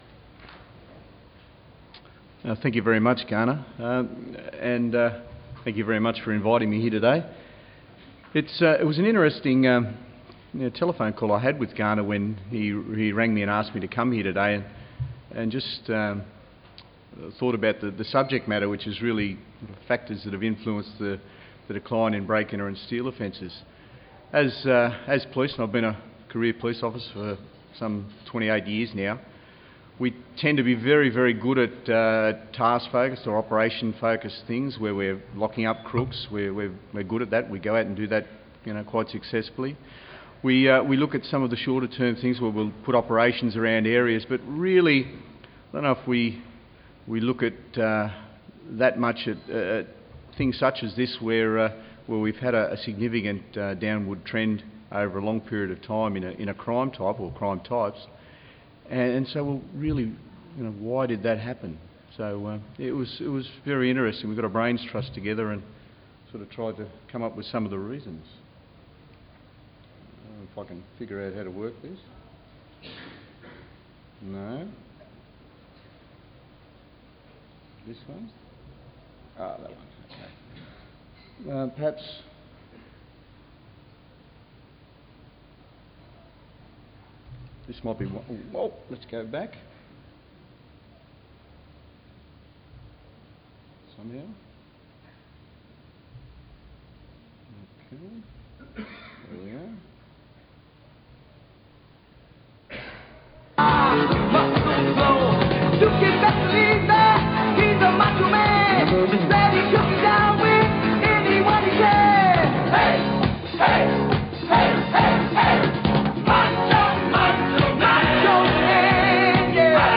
NSW Crime Statistics and Trends Seminar 24 April 2012
Audio of presentation: Consideration of Factors Influencing the Incidence of Break and Enter Offences